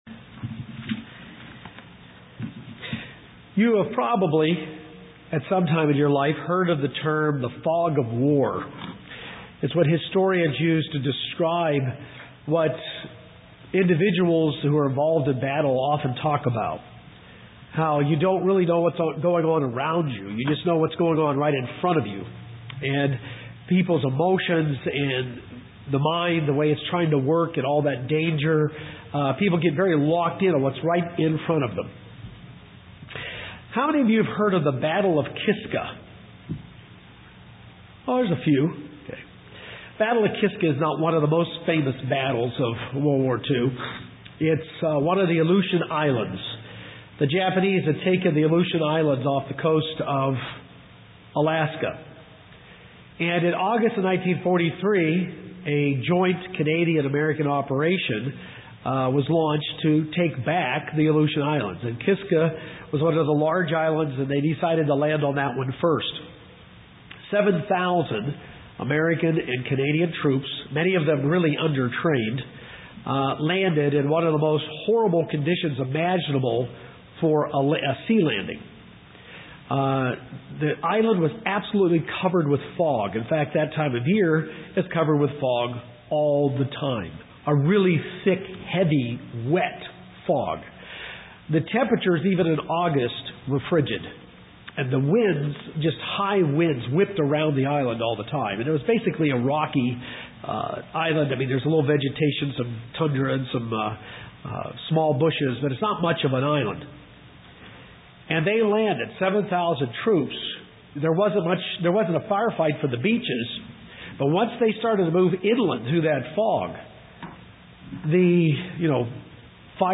Day five FOT New Braunfels.
UCG Sermon Transcript This transcript was generated by AI and may contain errors.